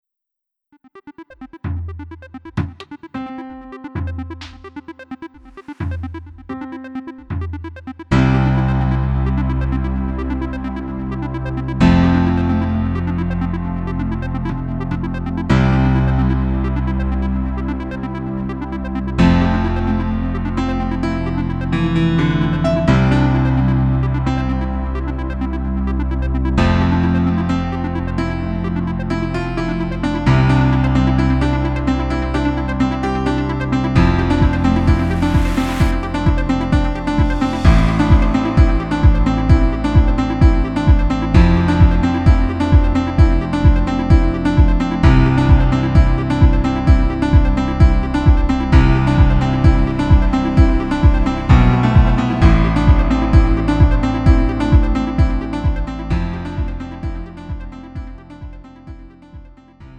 음정 원키 3:37
장르 구분 Lite MR